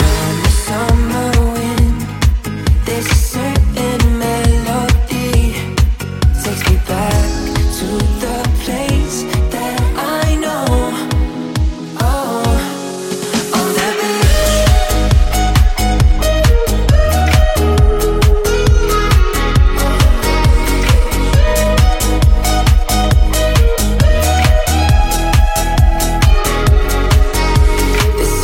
Genere: deep, house, club, remix